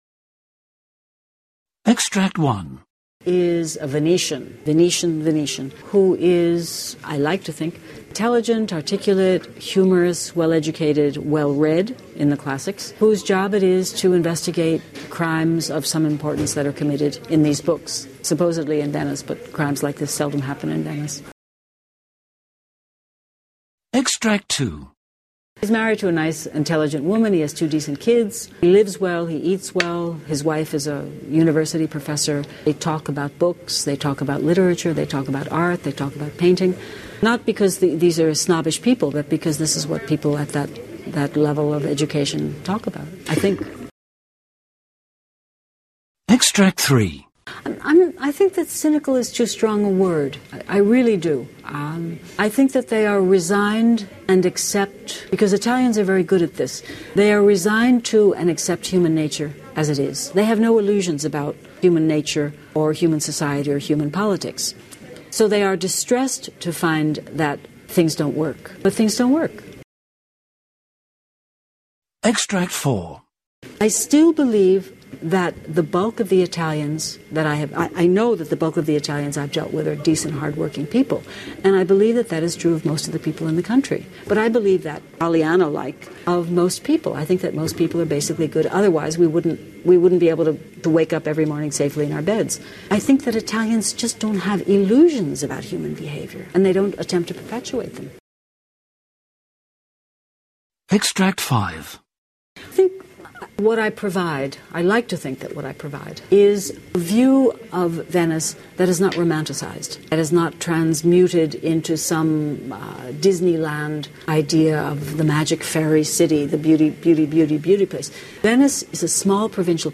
Interview with Donna Leon